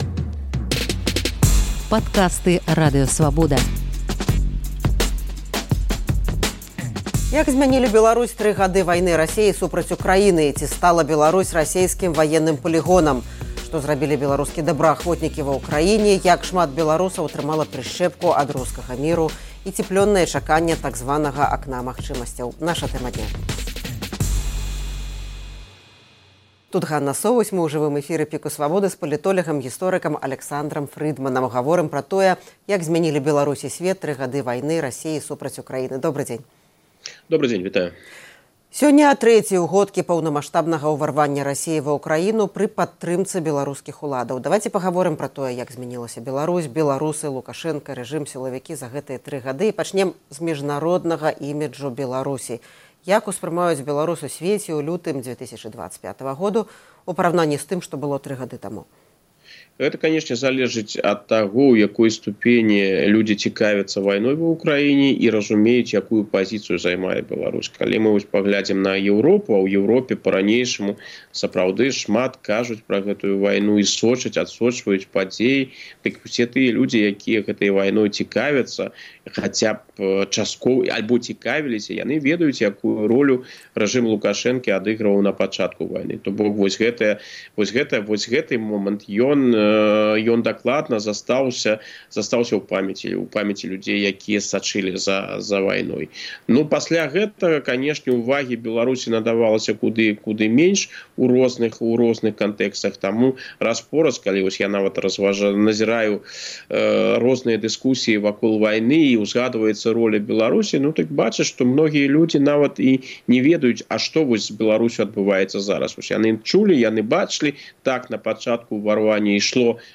Як зьмянілі Беларусь і сьвет тры гады поўнамаштабнай вайны Расеі супраць Украіны? Ці стала Беларусь расейскім ваенным палігонам? Што самае галоўнае зрабілі беларускія добраахвотнікі ва Ўкраіне? Абмяркоўваем з палітолягам і гісторыкам